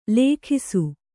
♪ lēkhisu